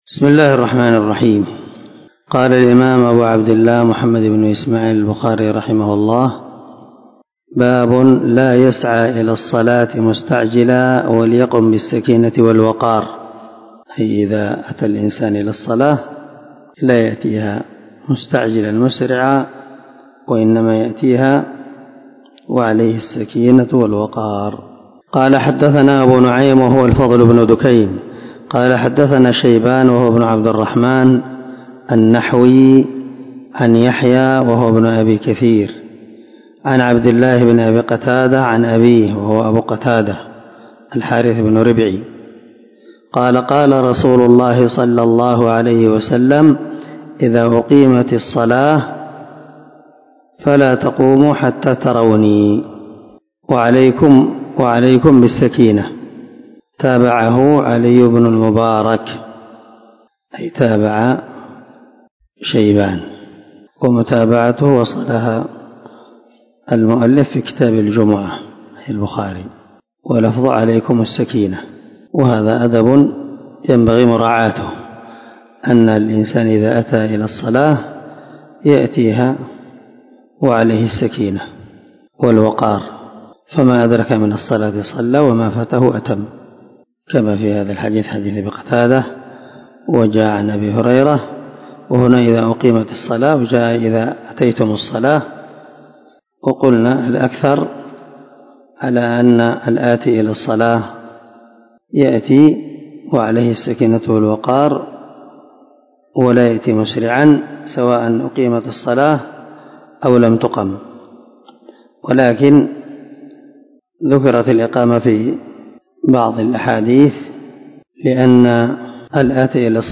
عنوان الدرس:
✒ دار الحديث- المَحاوِلة- الصبيحة.